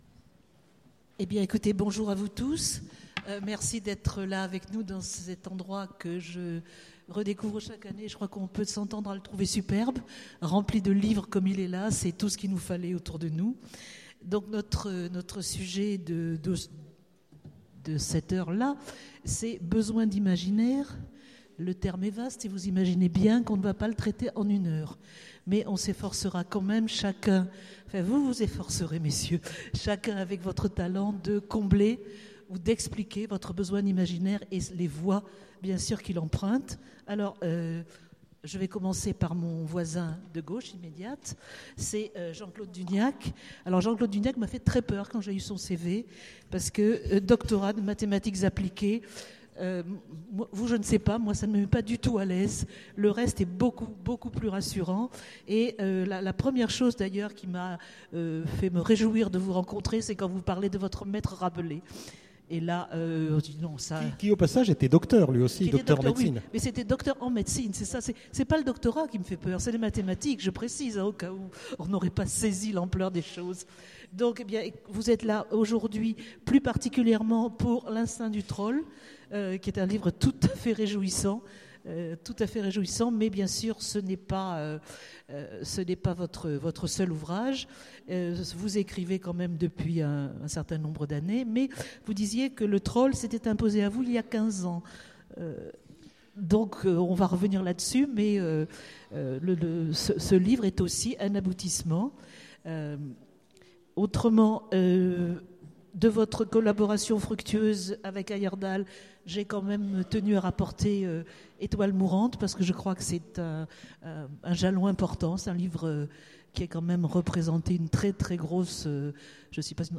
Étonnants Voyageurs 2015 : Conférence Besoin d'imaginaire